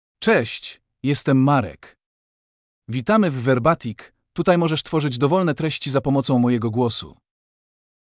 Marek — Male Polish (Poland) AI Voice | TTS, Voice Cloning & Video | Verbatik AI
MalePolish (Poland)
Marek is a male AI voice for Polish (Poland).
Voice sample
Listen to Marek's male Polish voice.
Male